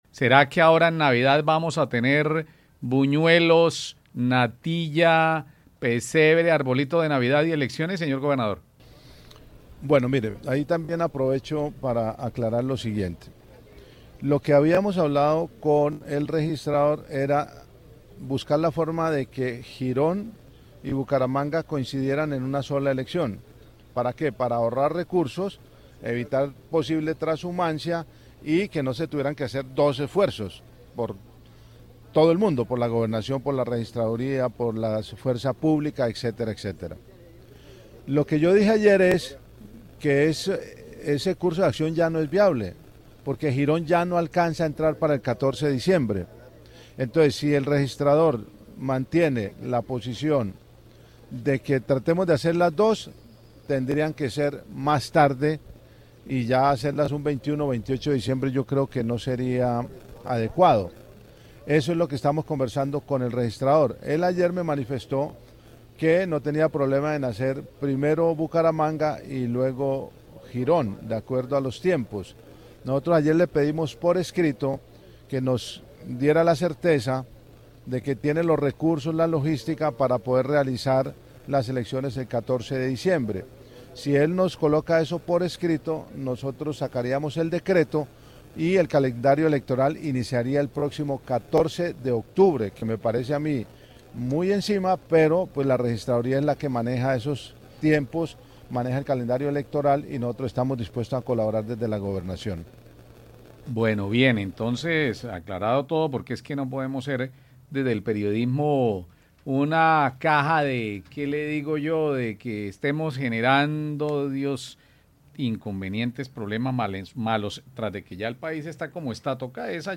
Gobernador de Santander habla de elecciones atípicas en Bucaramanga